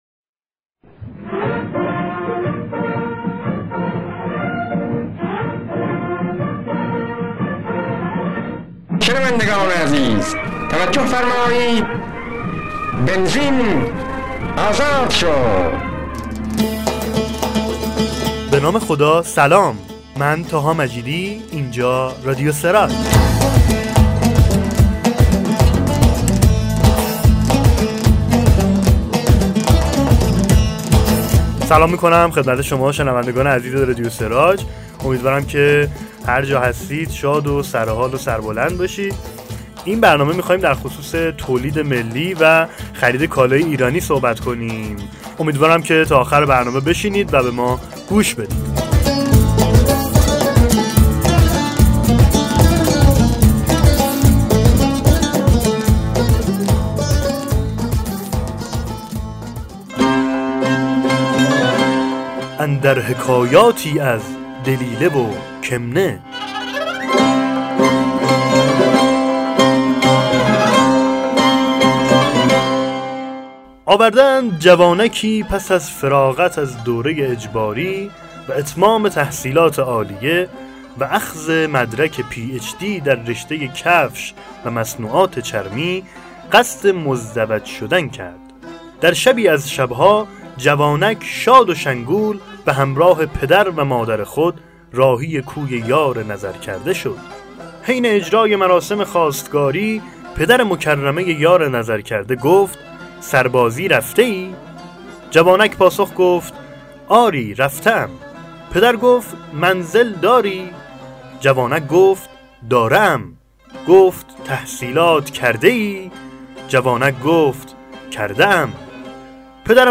به گزارش سراج24، ششمین شماره رادیو سراج در با بررسی موضوعات روز ایران و جهان در قالب طنز منتشر شد./این رادیو را می توانید از سمت چپ صفحه اصلی به صورت مستقیم و بدون دانلود گوش کنید.